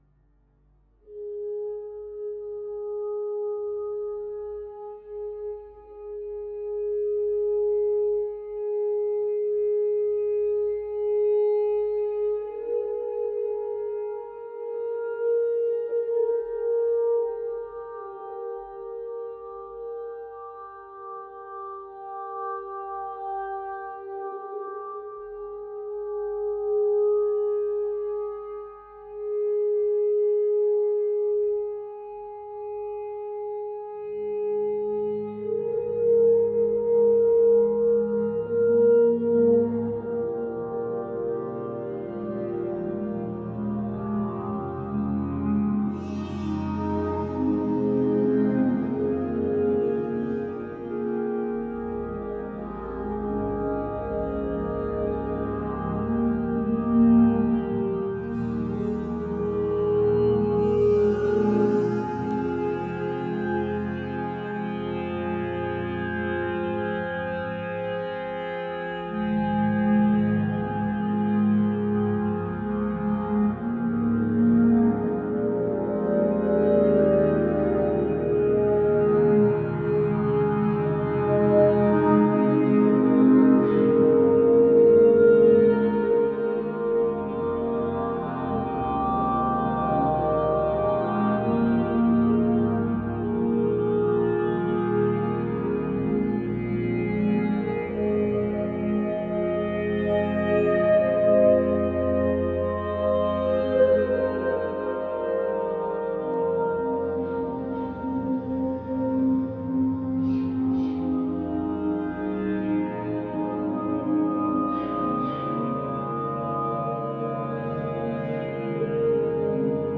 vocal group